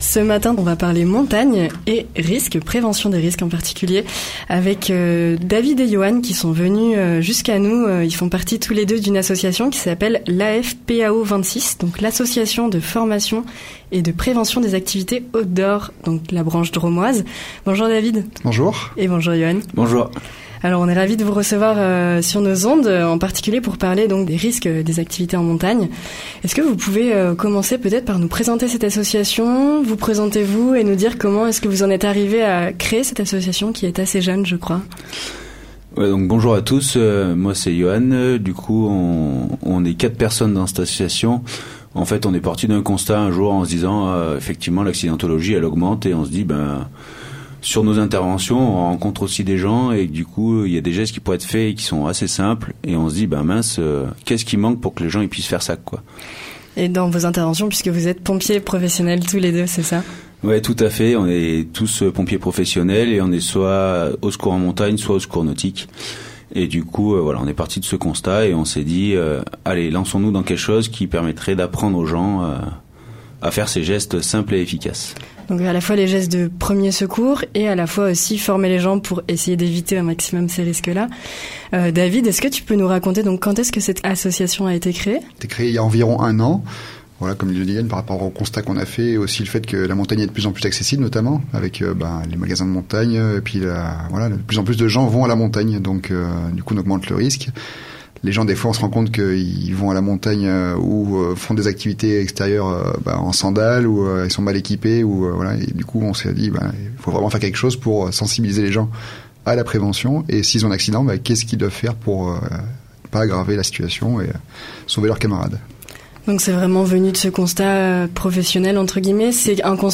À notre micro, ils reviennent sur le constat qui les a amenés à créer cette structure il y a un an, pour former et sensibiliser les pratiquant-es d’activités sportives en milieu naturel. Nous discutons de la notion de prise de risque et des premiers secours, des aléas extérieurs ou encore des spécificités des milieux montagnards et aquatiques du Vercors.